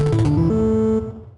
Techmino/media/effect/chiptune/clear.ogg at 89134d4f076855d852182c1bc1f6da5e53f075a4